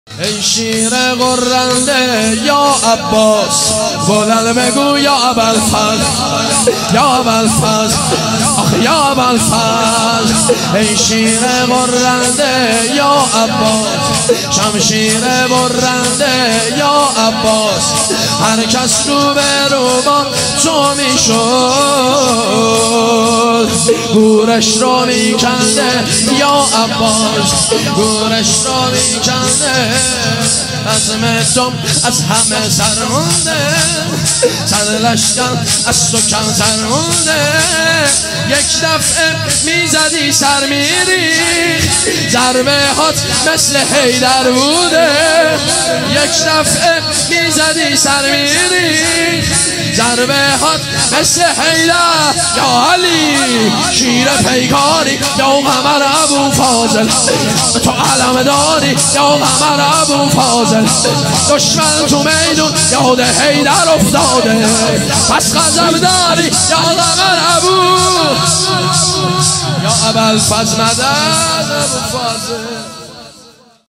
هیئت جنت العباس (ع) کاشان